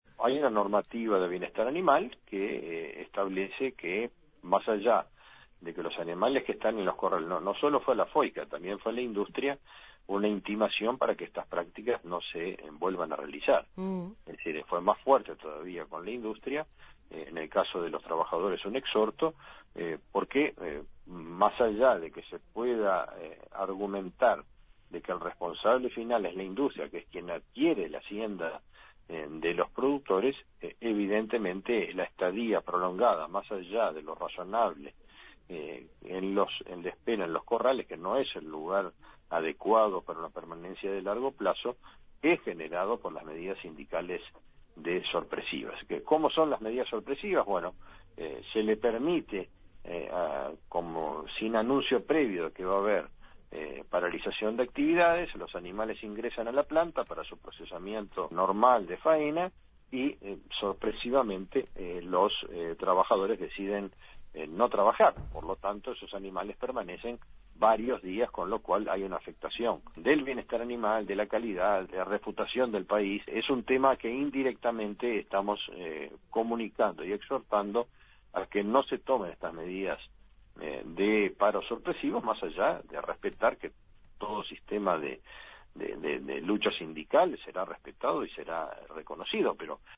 En Justos y pecadores entrevistamos al ministro de Ganadería, Agricultura y Pesca (MGAP)  Fernando Mattos